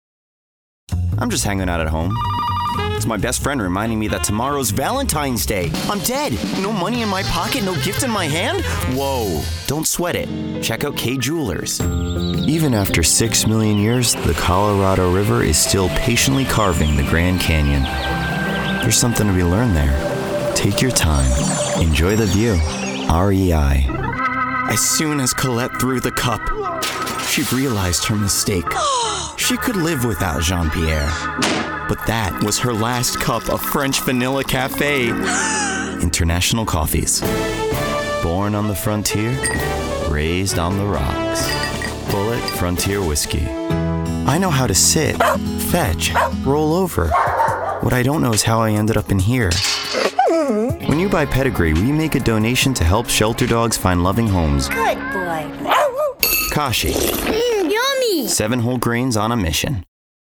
Commercial Demo
Young Adult
Middle Aged